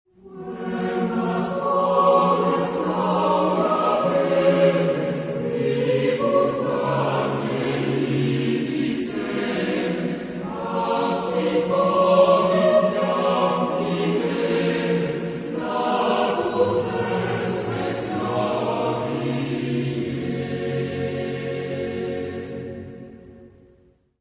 Genre-Style-Form: Sacred
Type of Choir: S + SATB  (5 mixed voices )
Tonality: G flat major